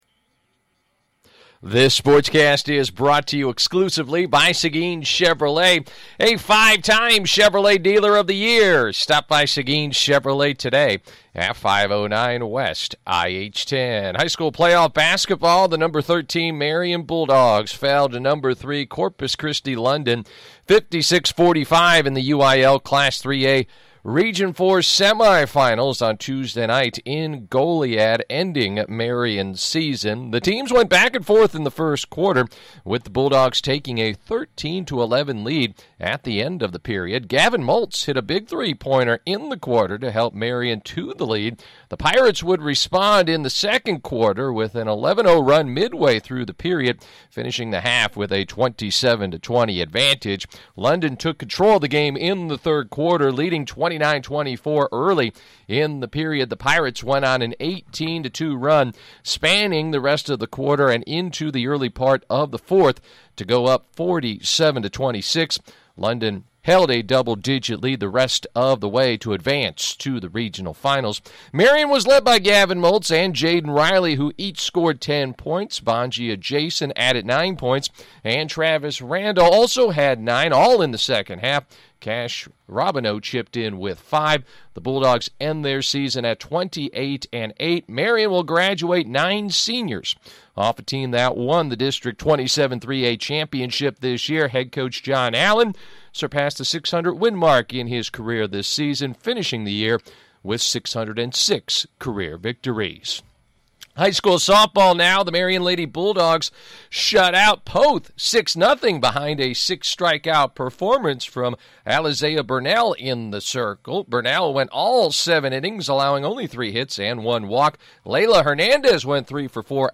Wednesday 3-4 Sportscast